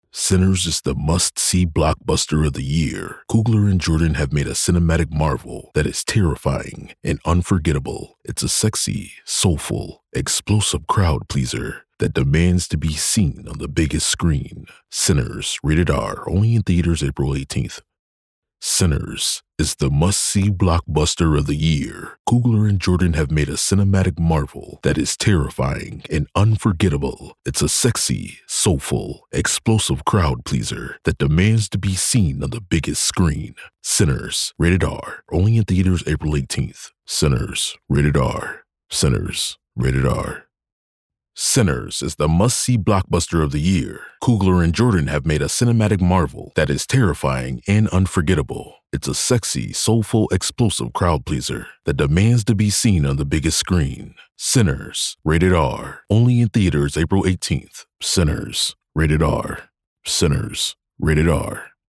Male
Yng Adult (18-29), Adult (30-50)
A voice that commands attention & delivers impact. A powerful, bold presence of 'Voice of God' when needed, seamlessly transitioning to conversational & upbeat, mid-range tone that's approachable, relatable. Authoritative, deep, versatile, inviting, inspiring, and motivational, ensuring your message has the perfect blend of strength and warmth.
Movie Trailers
All our voice actors have professional broadcast quality recording studios.